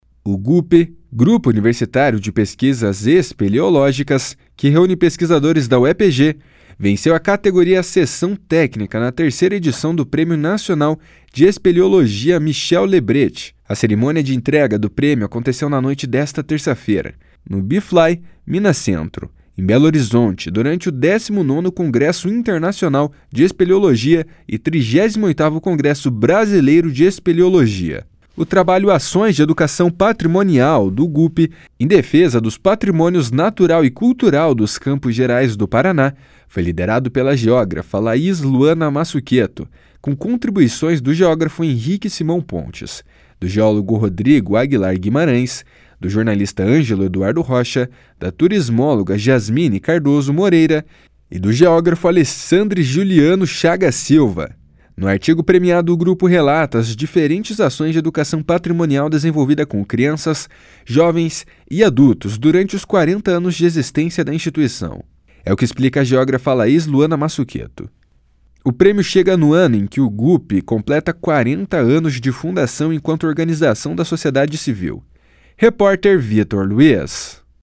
O prêmio chega no ano em que o Gupe completa 40 anos de fundação enquanto organização da sociedade civil. (Repórter: